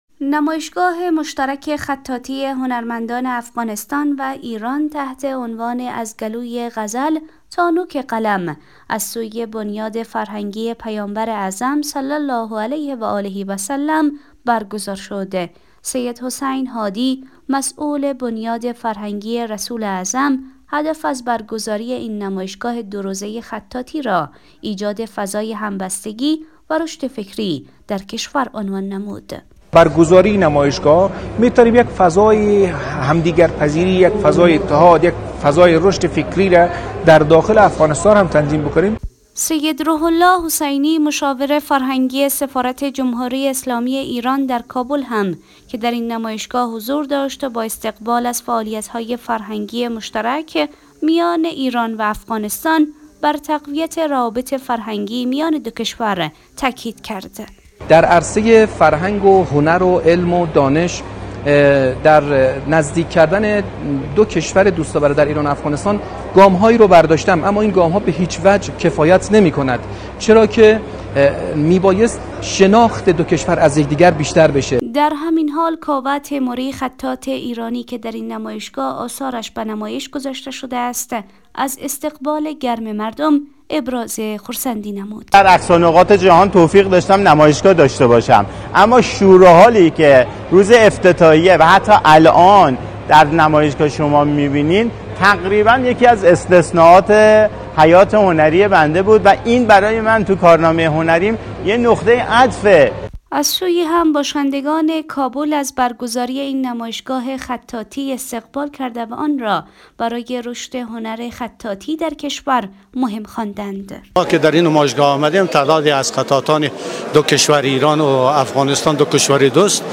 گزارش فرهنگی